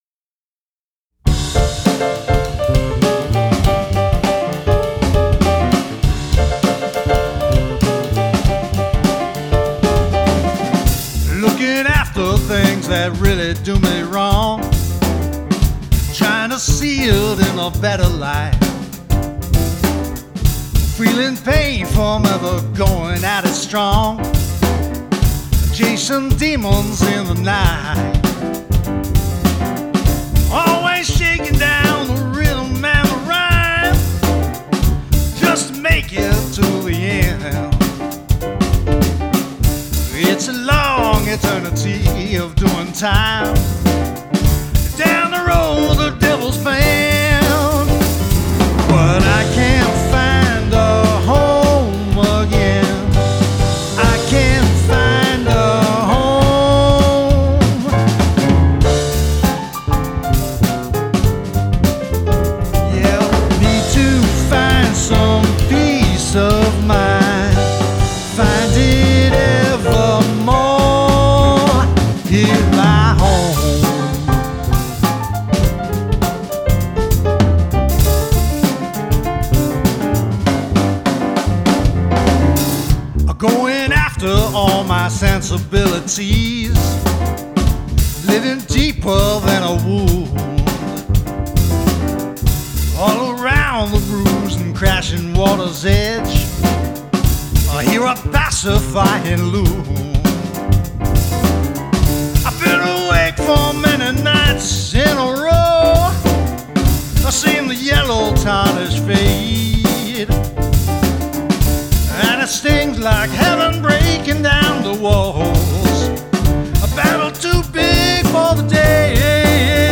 SINGER/MULTI-INSTRUMENTALIST & MUSIC PRODUCER
GRAMMY-recognized jazz musician
pianist, singer, trumpeter, and composer